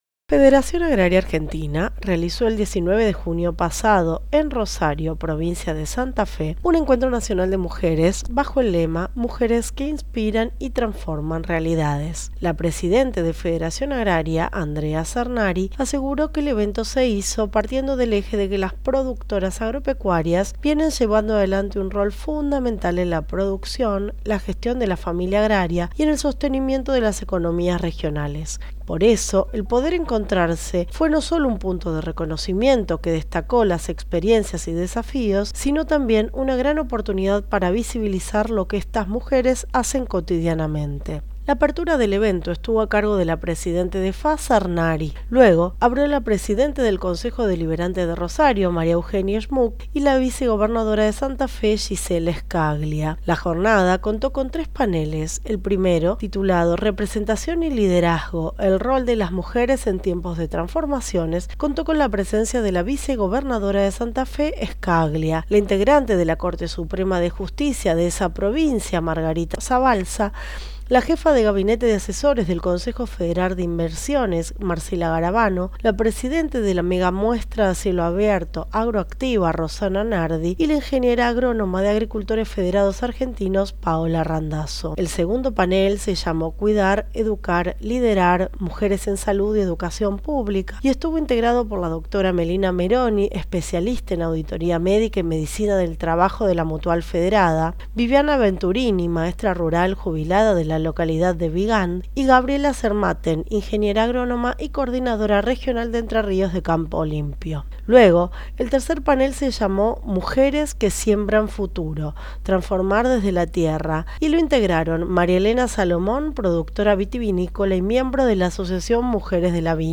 Noticia-FAA-realizó-un-encuentro-nacional-de-mujeres.mp3